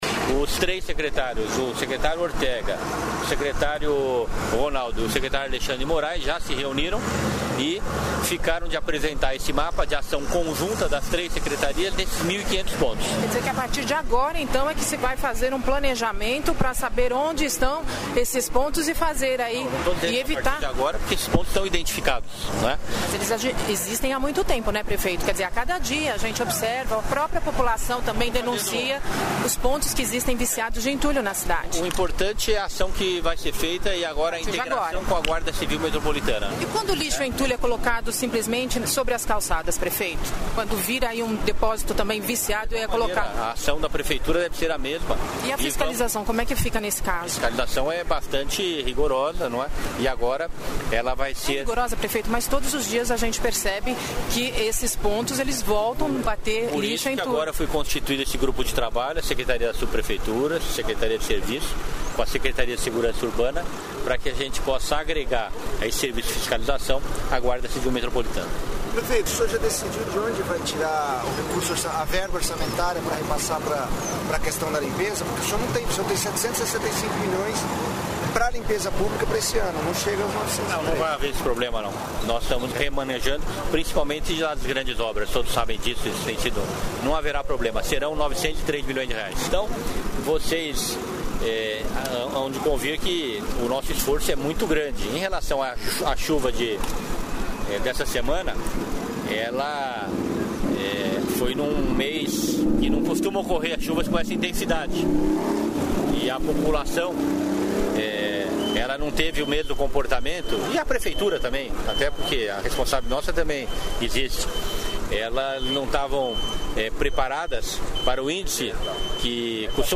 Ouça trecho da entrevista com o prefeito Gilberto Kassab (DEM)